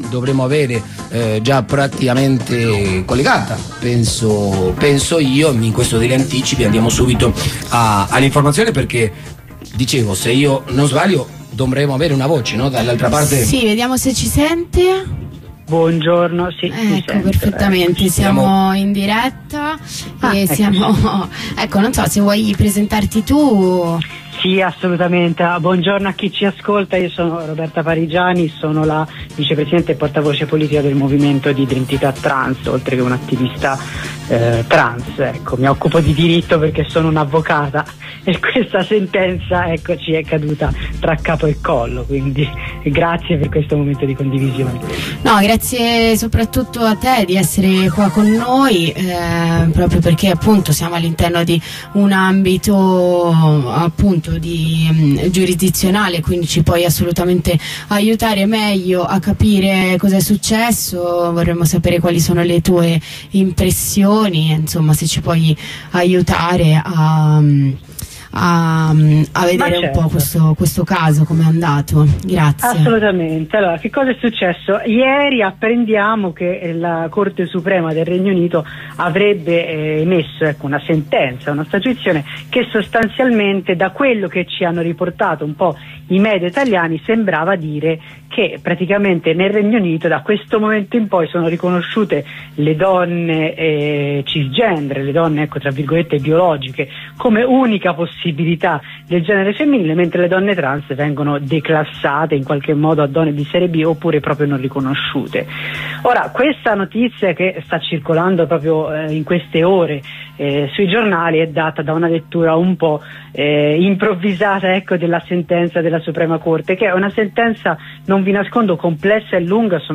Comunicazione telefonica